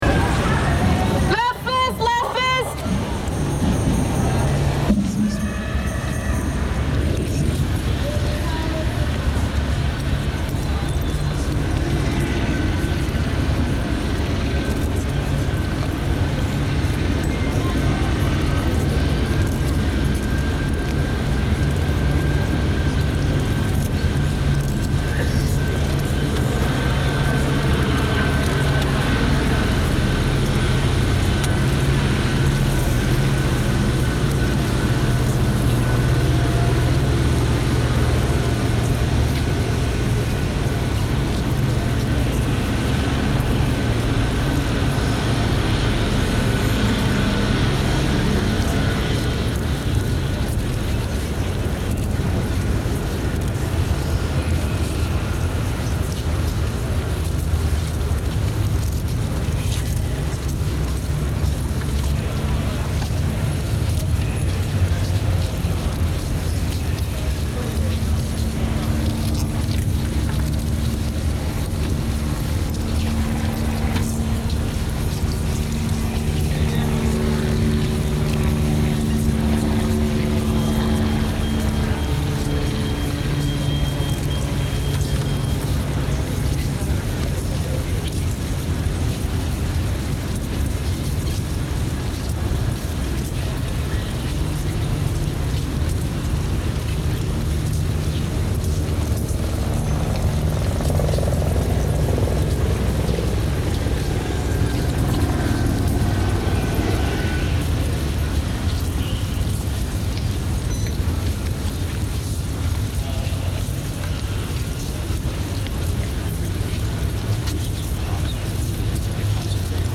an archive of site recordings, bootleg remixes and silences